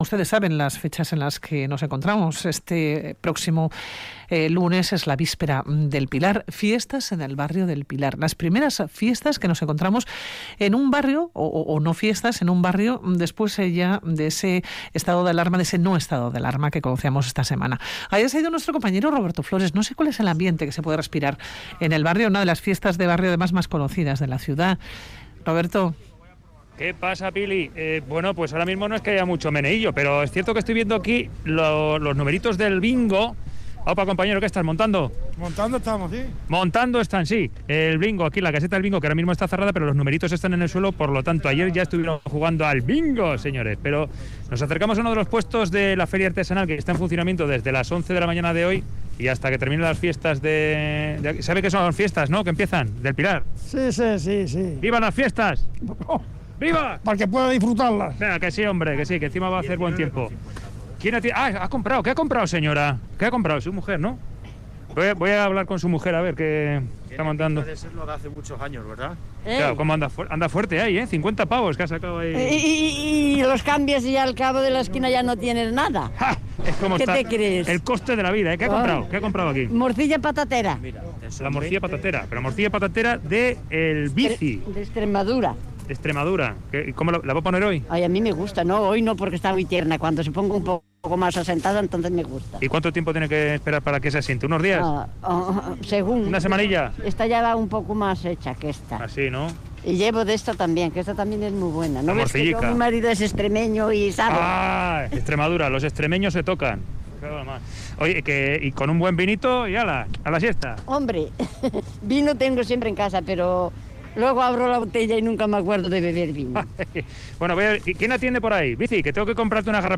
Audio: Y en lo que comienzan los actos más emblemáticos, charlamos con los propietarios de los puestos que conforman la Feria Gastronómica, y sus clientes.